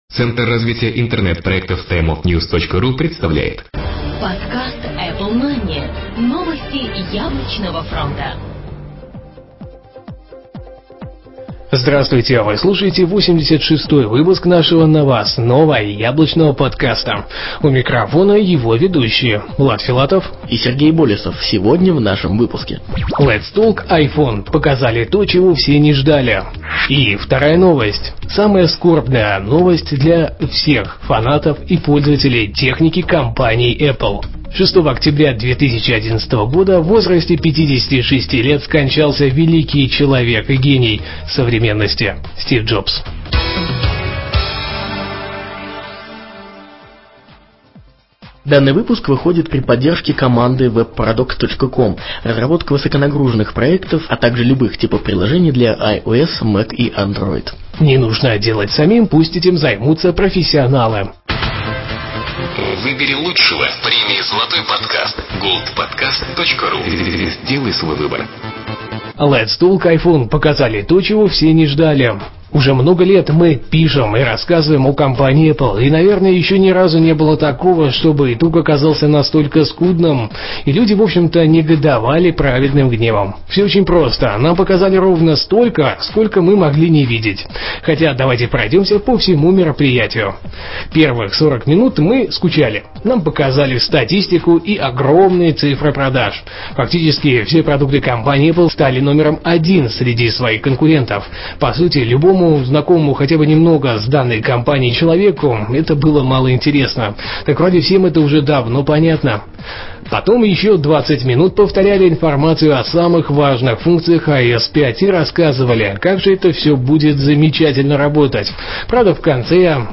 Жанр: новостной Apple-podcast
stereo